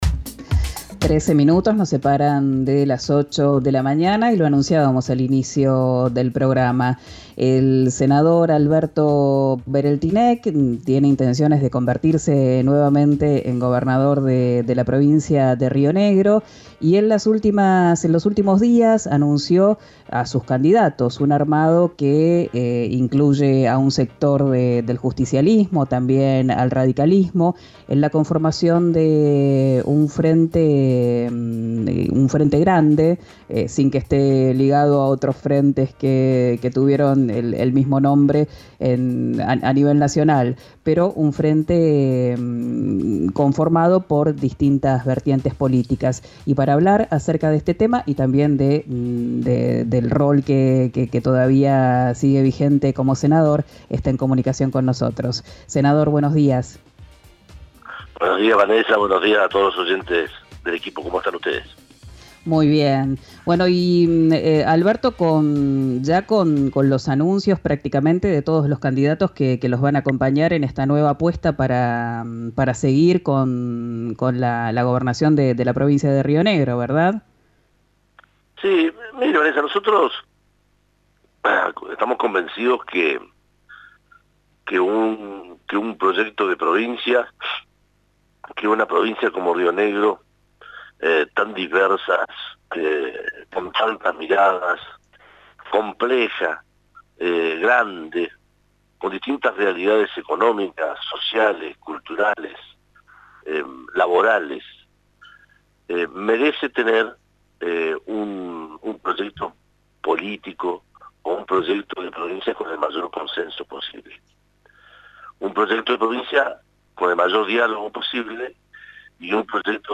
El senador concedió varias entrevistas radiales para explicar el 'Gran Acuerdo Provincial'.